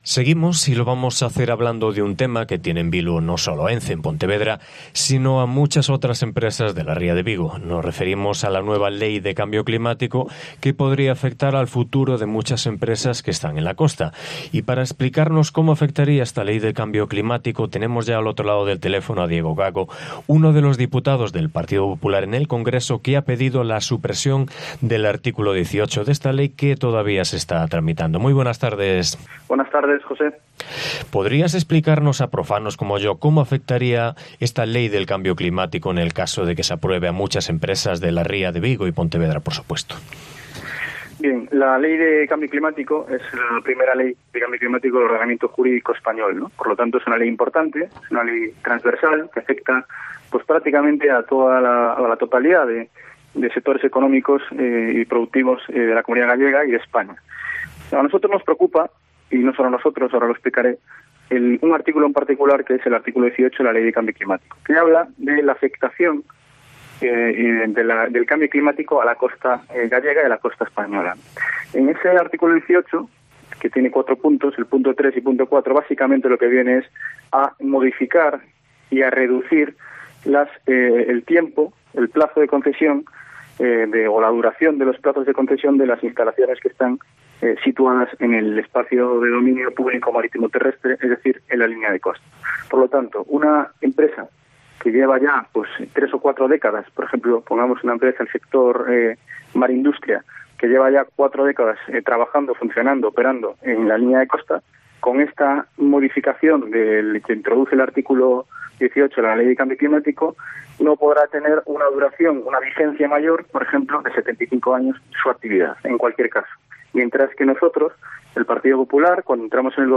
ENTREVISTA
Entrevista a Diego Gago, presidente de Nuevas Generaciones